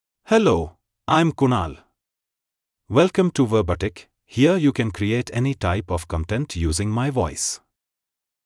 MaleEnglish (India)
Kunal is a male AI voice for English (India).
Voice sample
Male
English (India)
Kunal delivers clear pronunciation with authentic India English intonation, making your content sound professionally produced.